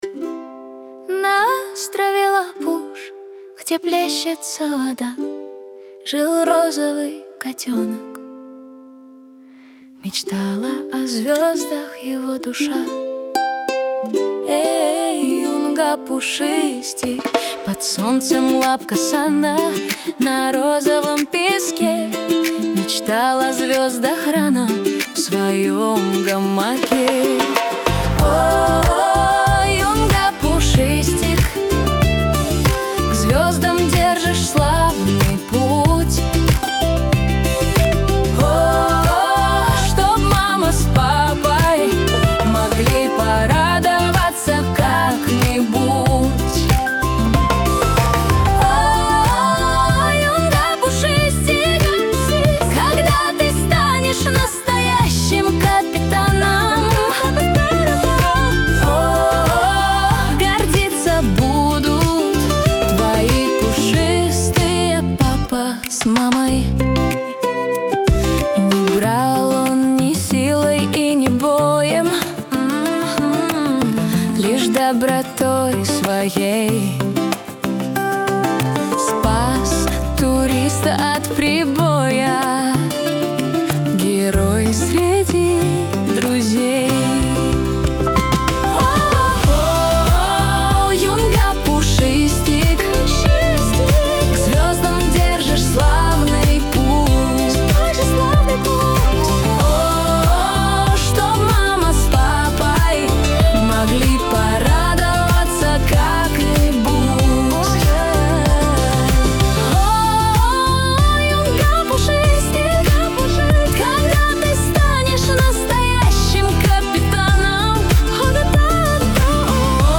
Саундтрек